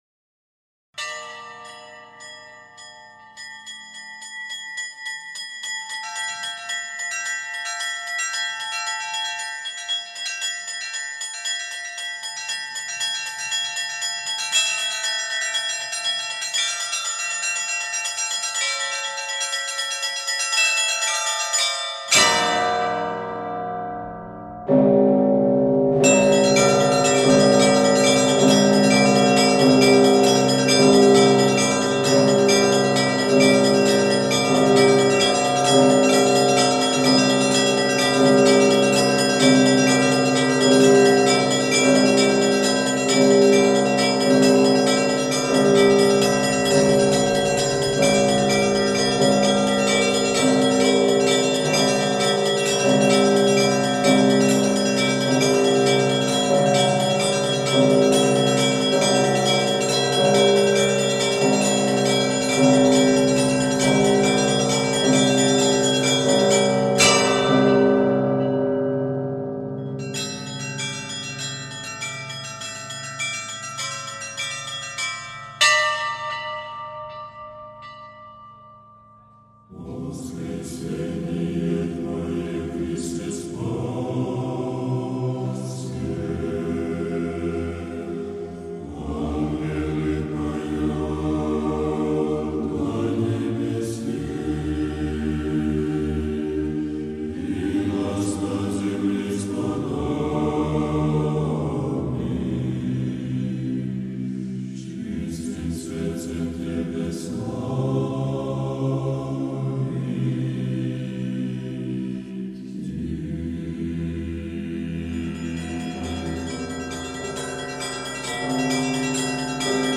Опознайте пожалуйста это духовное песнопение
В этом отрывке поёт - братия. Скорее всего, это братский хор Свято-Успенской Почаевской Лавры. У них это исполнение под названием - "Пасхальный звон и стихира крестного хода".